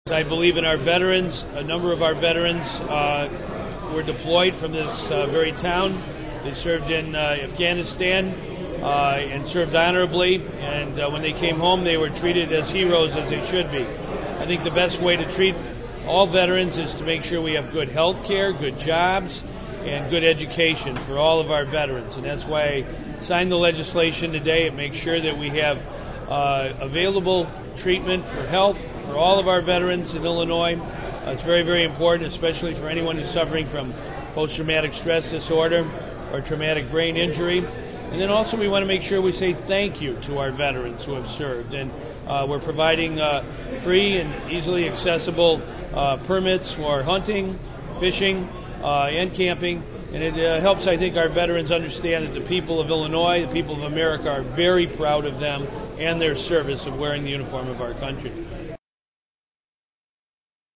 The first bill, House Bill 6103, states veterans who receives services from a state mental health facility will not be charged for services covered by their existing insurance plan. The second law signed woudl allow veterans fishing and hunting licenses free of charge. Governor Pat Quinn explains.
That was Governor Pat Quinn.